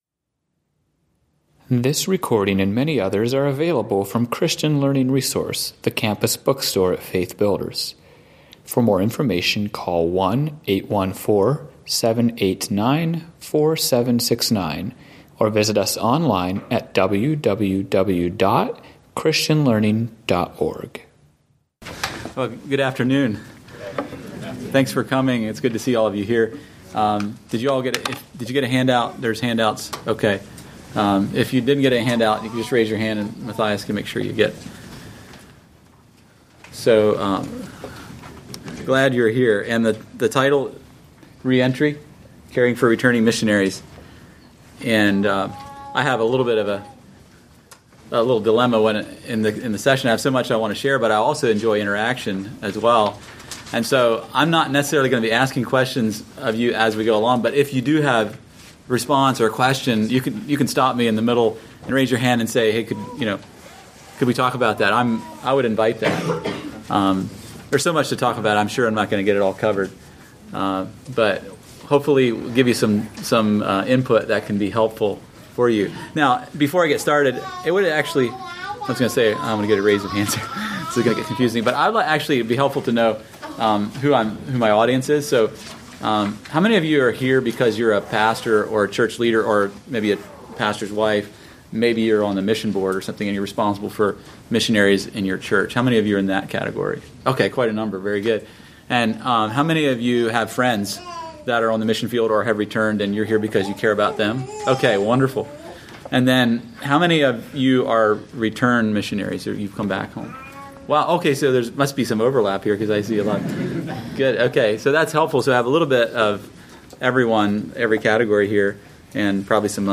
Home » Lectures » Re-entry: Caring for Returning Missionaries